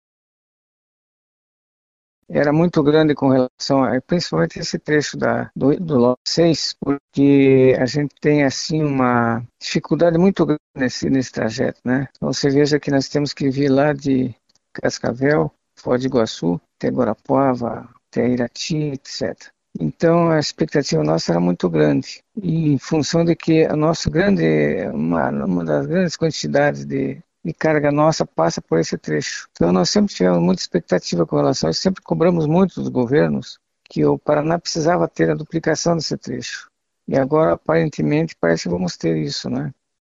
Em entrevista à CBN Curitiba